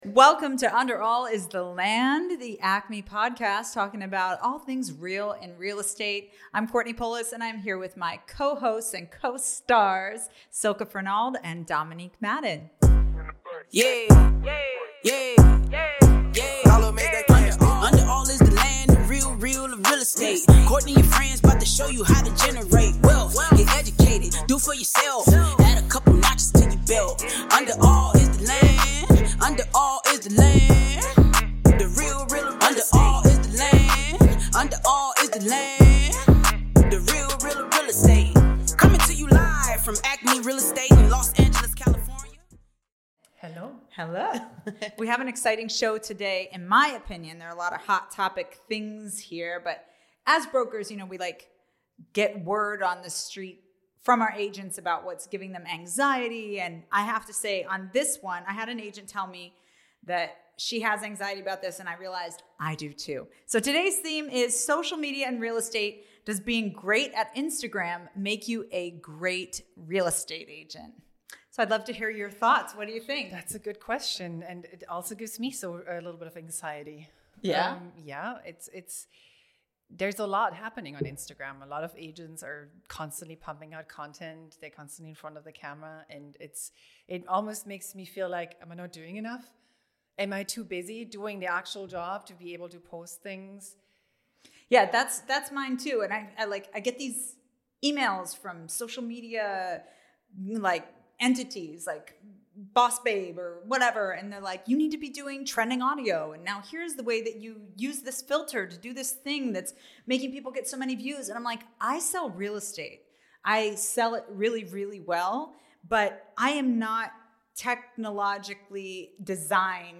Under All is The Land podcast hosts meet this week to discuss whether social media is the ultimate gauge of agent performance and expertise, and how much weight a good following on IG should have on how consumers choose their representation.